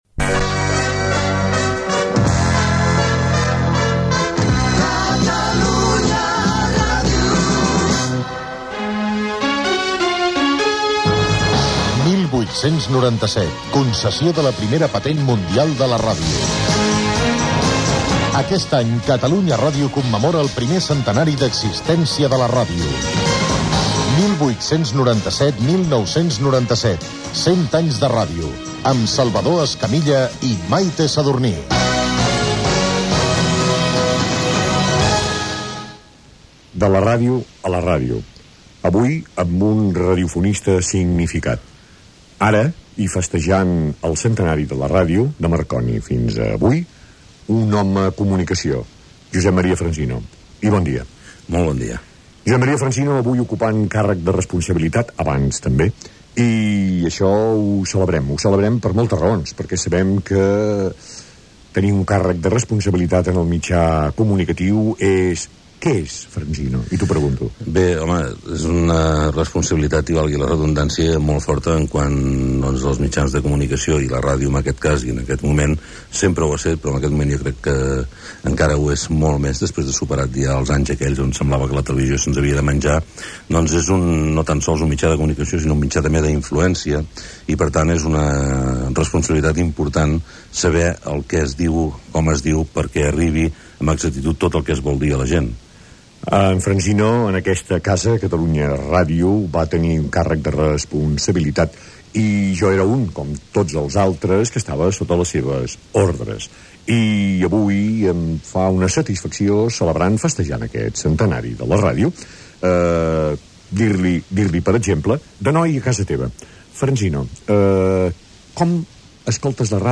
Careta del programa.
Divulgació
Escamilla, Salvador